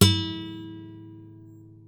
Primera cuerda de una guitarra
cordófono
guitarra